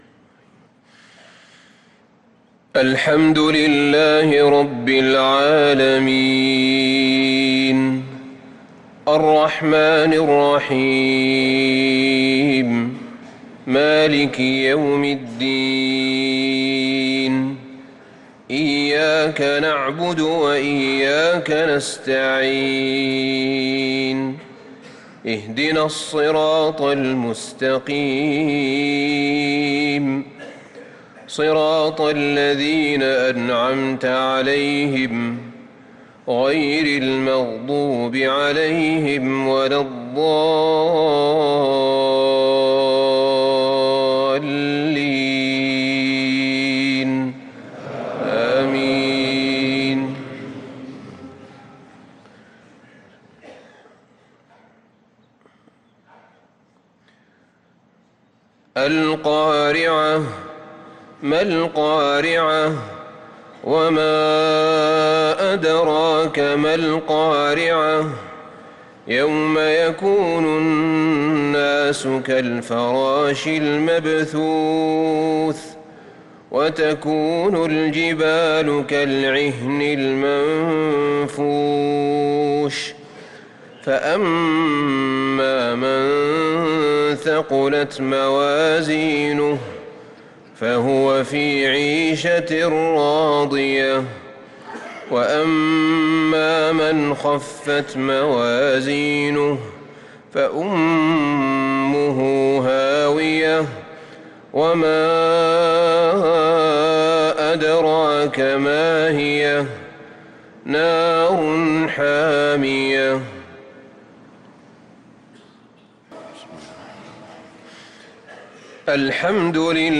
صلاة المغرب للقارئ أحمد بن طالب حميد 17 ذو الحجة 1444 هـ